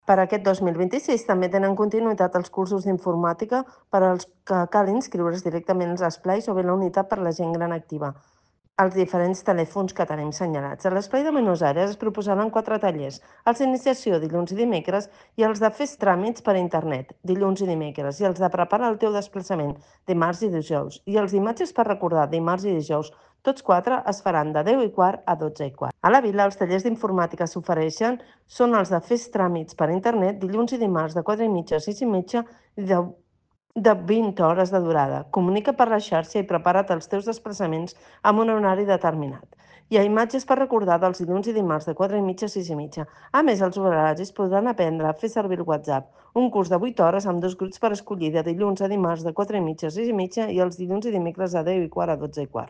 Montserrat Salas, regidora de Gent Gran de l'Ajuntament de Martorell